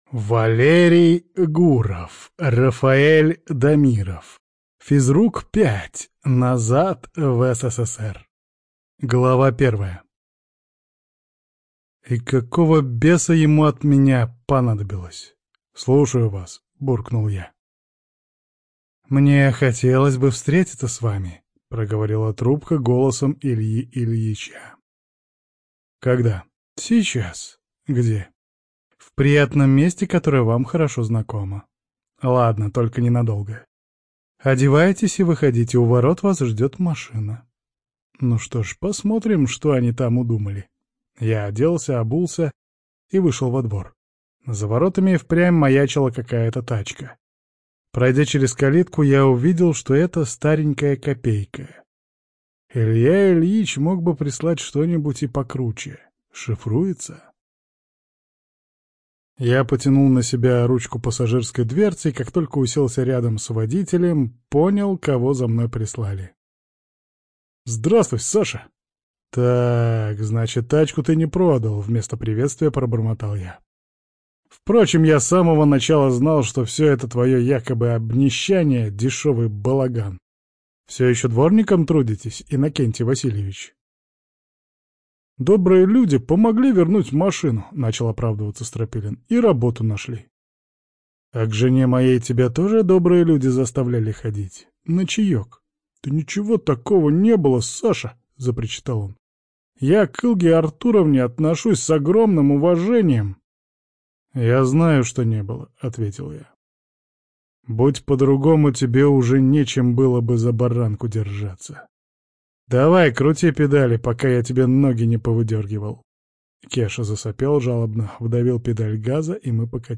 ЖанрАльтернативная история